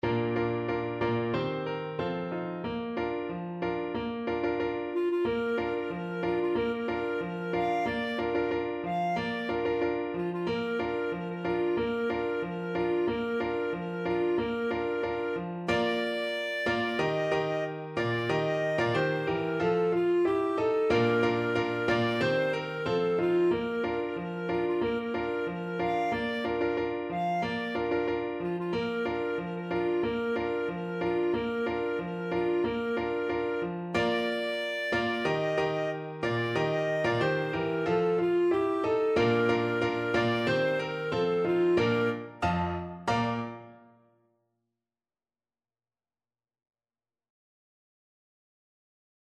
Clarinet
Traditional Music of unknown author.
2/2 (View more 2/2 Music)
Bb major (Sounding Pitch) C major (Clarinet in Bb) (View more Bb major Music for Clarinet )
Happily =c.92
ten_in_the_bed_CL.mp3